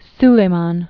(slā-män, -lə-) Known as “Suleiman the Magnificent” and “the Lawmaker.” 1494?-1566.